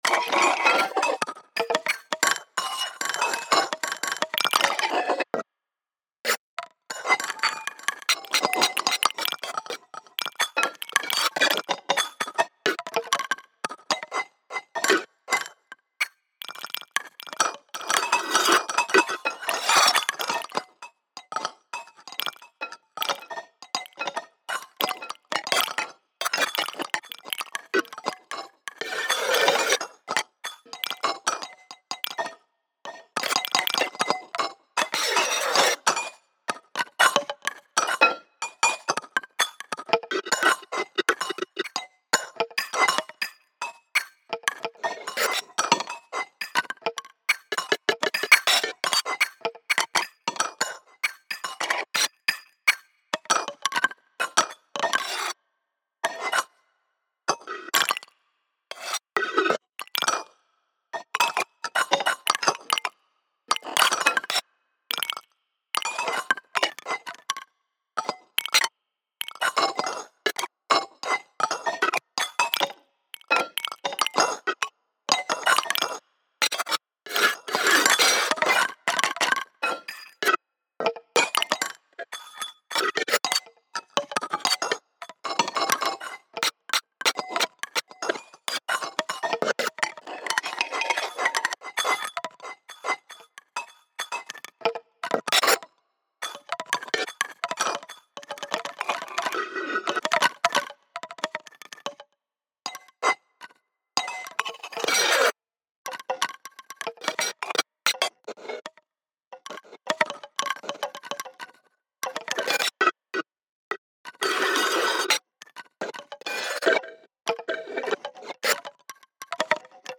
Improvisations for AL Set